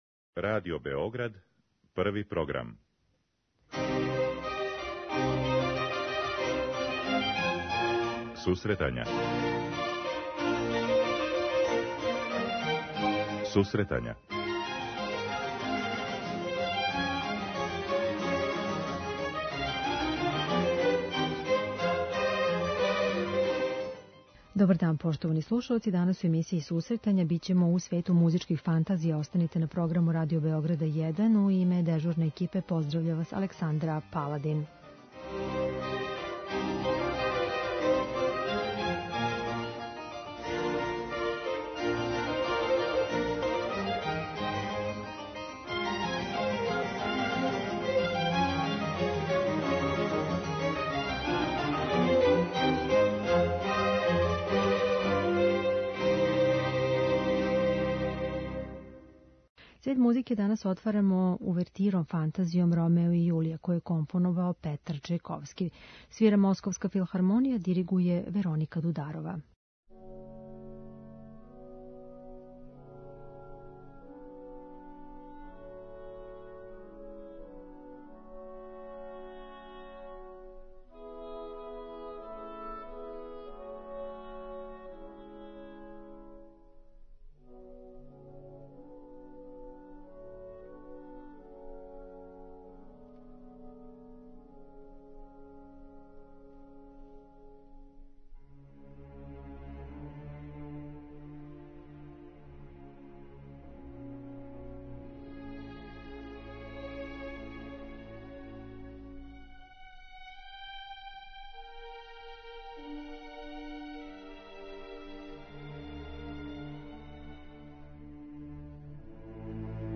преузми : 10.18 MB Сусретања Autor: Музичка редакција Емисија за оне који воле уметничку музику.